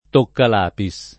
toccalapis [ tokkal # pi S ] s. m.